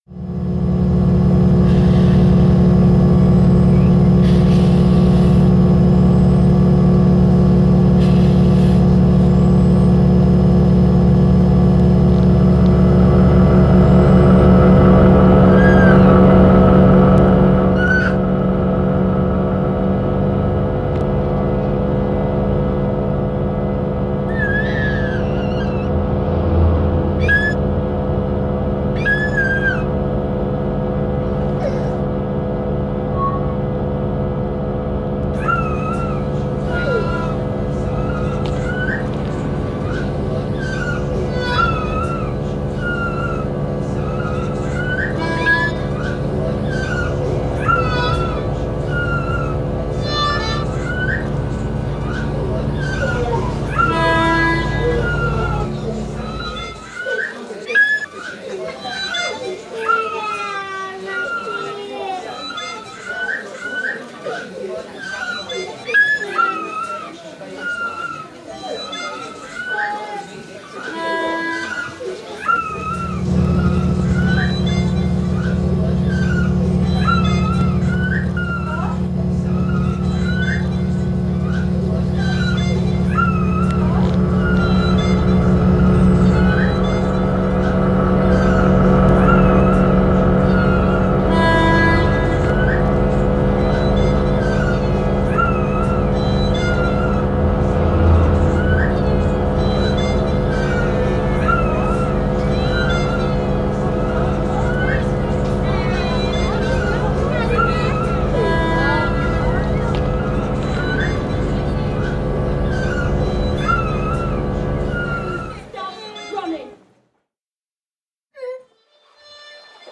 I’ve created an audio collage in three parts composed solely of sounds recorded during my time in Cork. With my portable recorder, I moved through the city guided by suggestions from Corkonians, my ears and serendipity.
Working within a strict set of limitations I used only the material I could find in the city to produce the collage, making conventionally ‘musical’ passages solely from found sound. I avoided effects (apart from some EQ scrubbing up) because I wanted to keep it visceral and life-like. I felt driven to create a contemporary, urban soundtrack when the voices featured are those of skateboarders and hoodie-clad party-goers.
Cork sounds much like any other Irish city but the undulous Cork accent is truly unique.
The emotional content of the direct speech is soundtracked and enhanced by the abstract, "instrumental" elements in the collage.